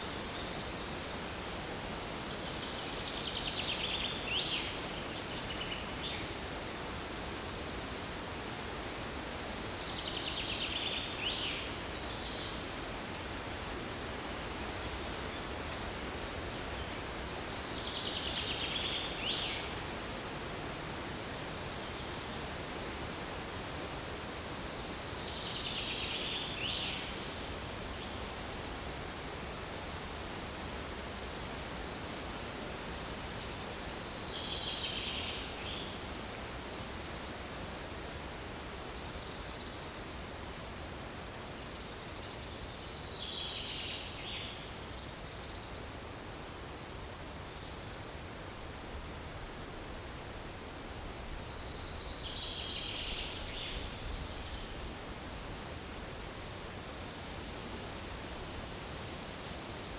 PEPR FORESTT - Monitor PAM - Renecofor
Certhia familiaris
Certhia brachydactyla